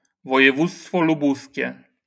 ルブシュ県Lubusz Voivodeship (ポーランド語: województwo lubuskie [vɔjɛˈvut͡stfɔ luˈbuskʲɛ] (